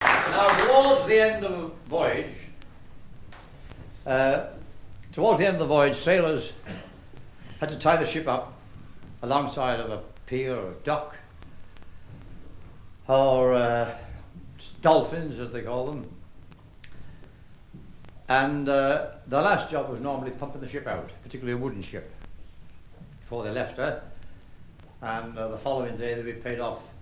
explication sur des chansons maritimes
Catégorie Témoignage